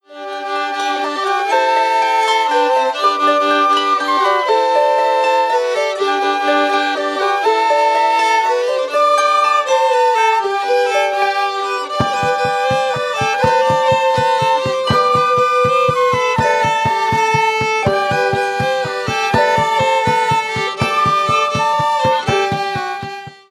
All tunes are traditional Irish, unless otherwise noted.